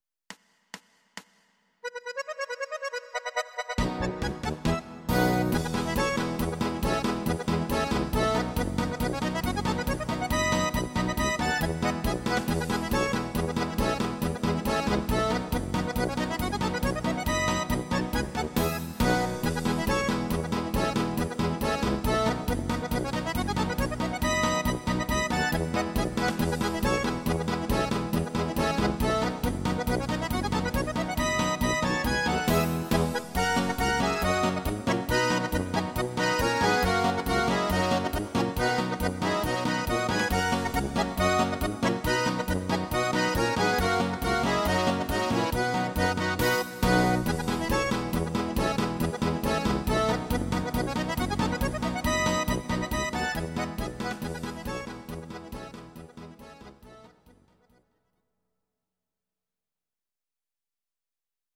instr. steirische Harmonika